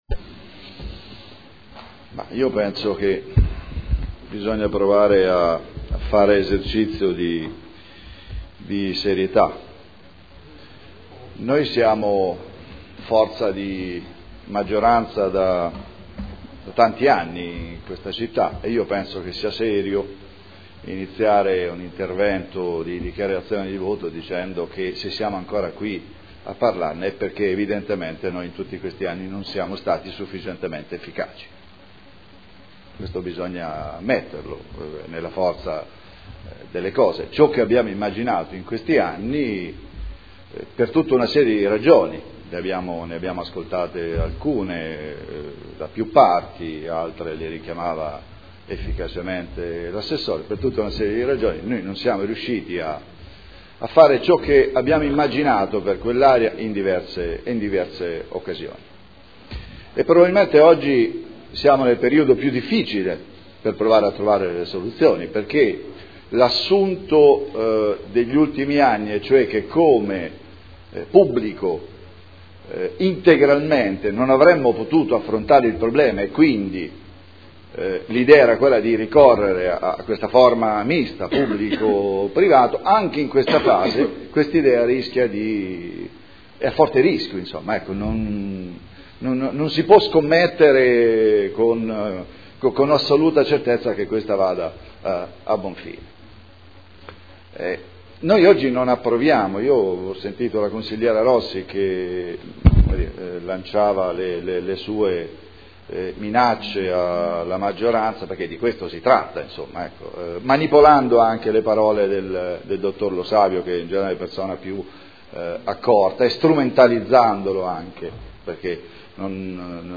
Paolo Trande — Sito Audio Consiglio Comunale